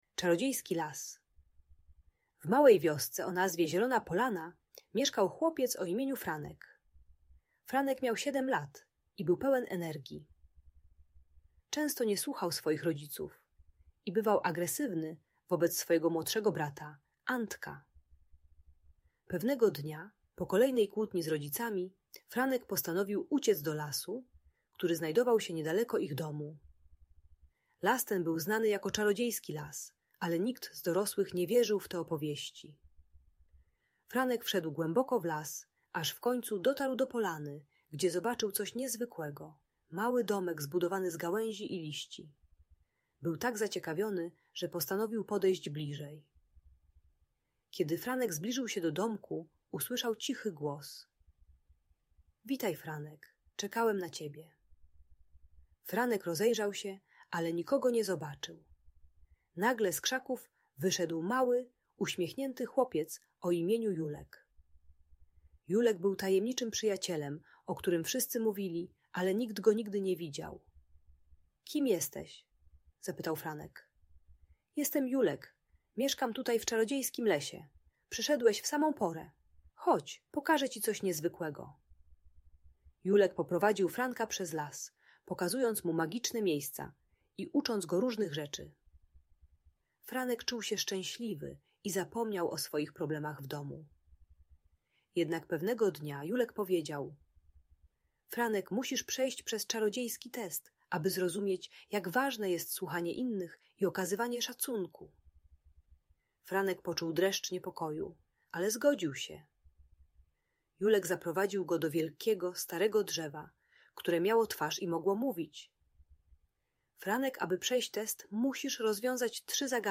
Czarodziejski Las - Agresja do rodziców | Audiobajka